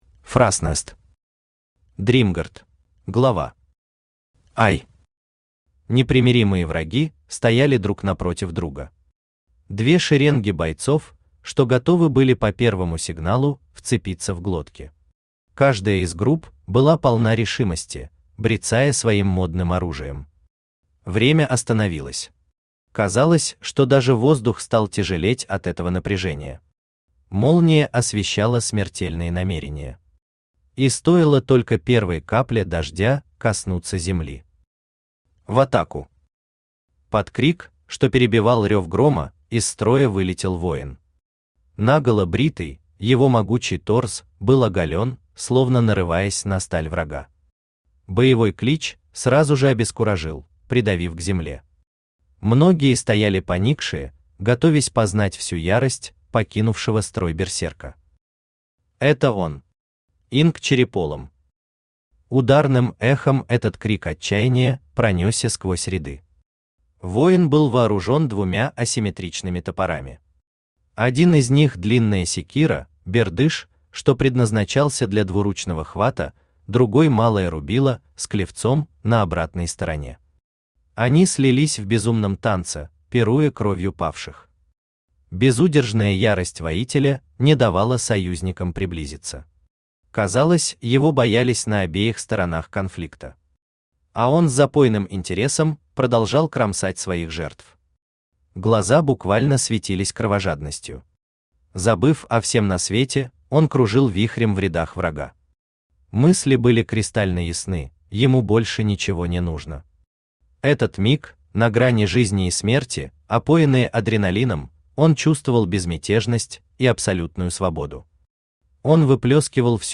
Читает: Авточтец ЛитРес
Аудиокнига «Дримгард».